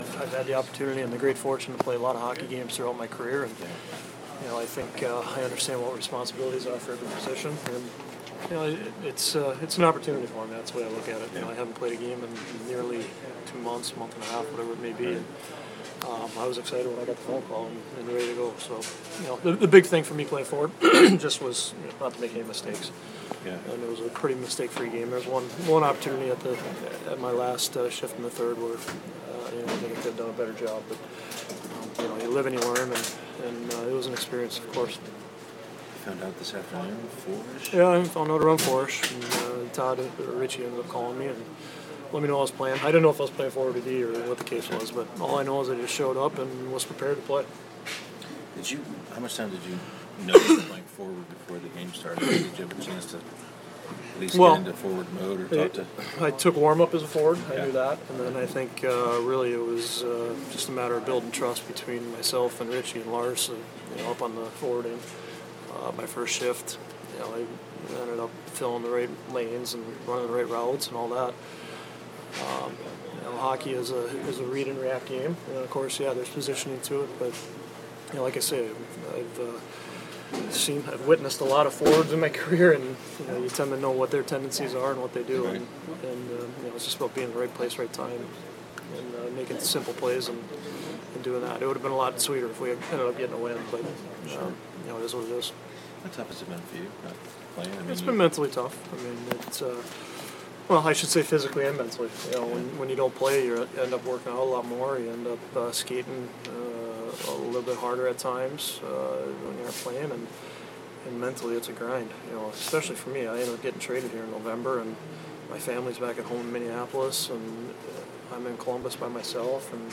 Jordan Leopold post game - Jan. 31st